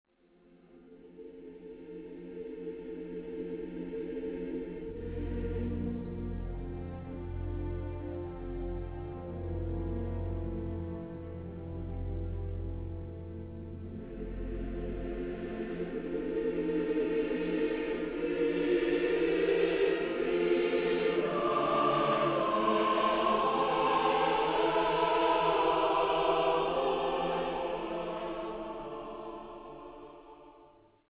szoprán és bariton szólóra, kórusra és zenekarra